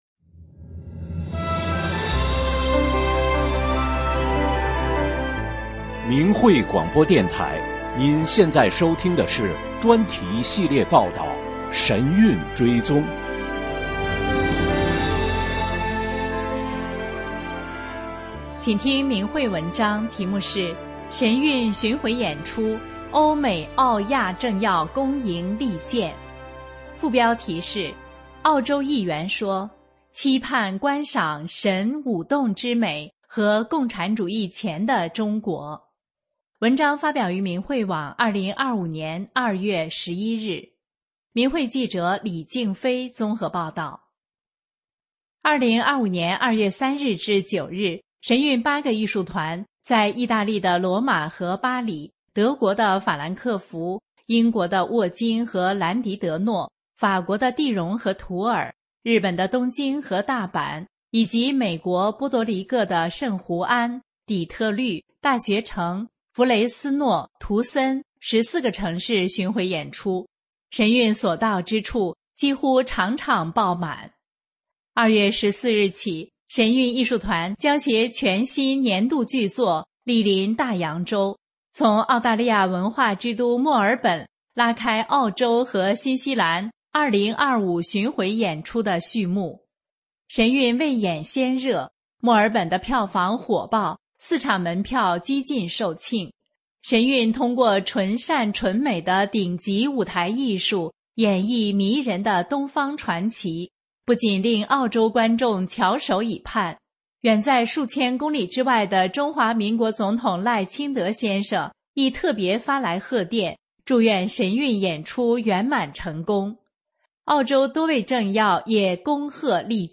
真相广播稿 神韵巡回演出 欧美澳亚政要恭迎力荐 发表日期： 2025年2月13日 节目长度： 14分50秒 在线收听 下载 3,994 KB 3,477 KB 下载方法 ：按鼠标器右键，在弹出菜单中选择“目标文件保存为…”（Save Target A s…）